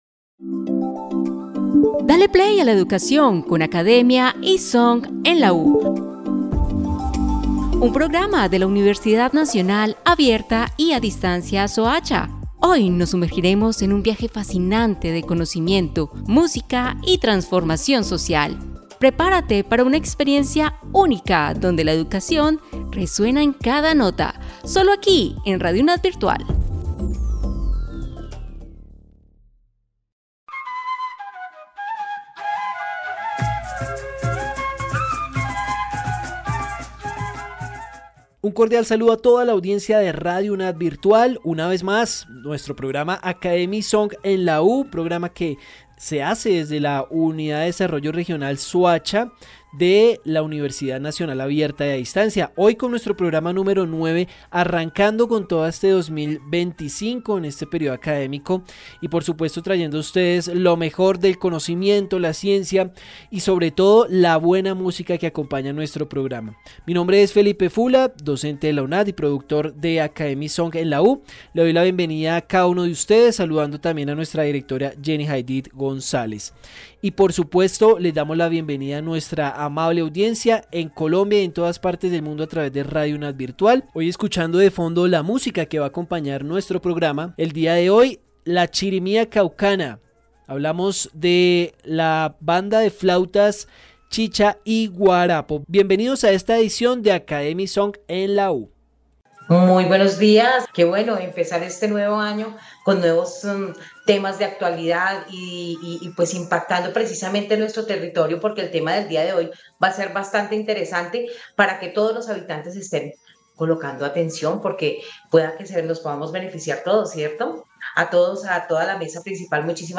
Academia y Song en la U, es un programa radial de la UDR Soacha, donde docentes y estudiantes discuten temas de interés para la comunidad, destacando a la UNAD como motor de desarrollo y transformación social. El programa incluye un tema del día, secciones sobre el trabajo de la UNAD en la región, análisis de temas actuales, y relatos inspiradores de la comunidad académica. Además, la música que acompaña el programa, vincula artistas, géneros o estilos con el tema principal.